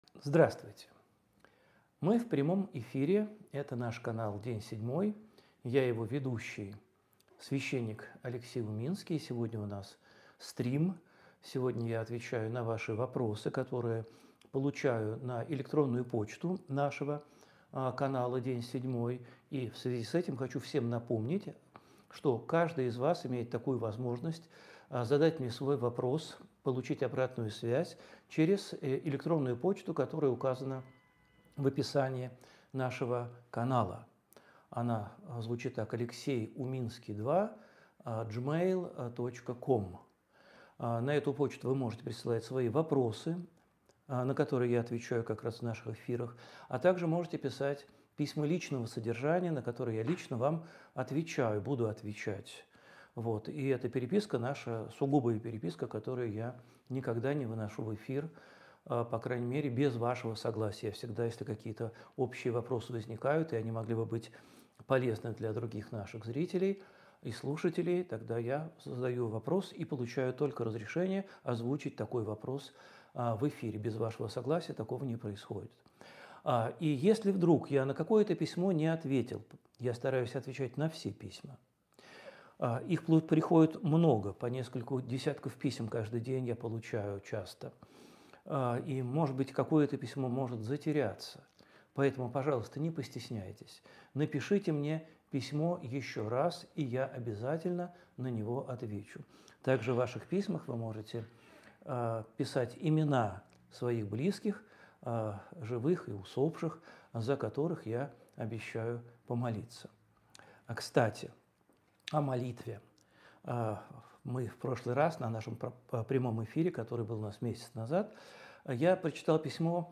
Эфир ведёт Алексей Уминский